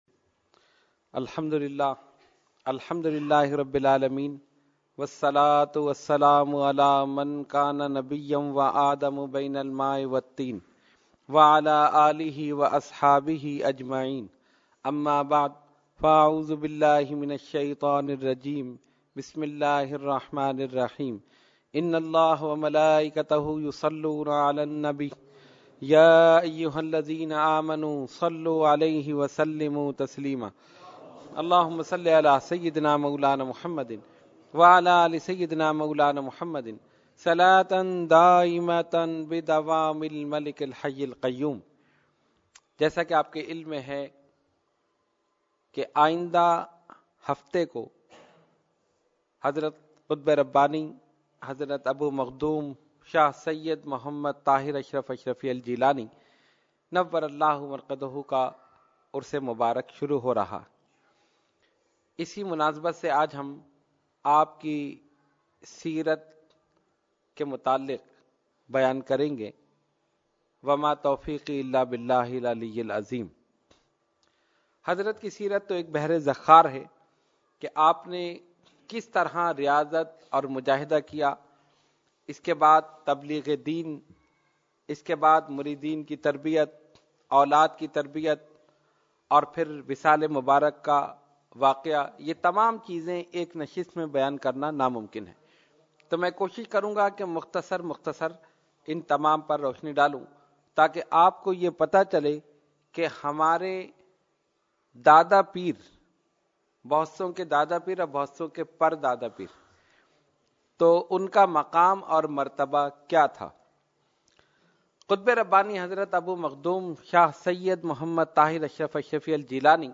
Category : Speech | Language : UrduEvent : Weekly Tarbiyati Nashist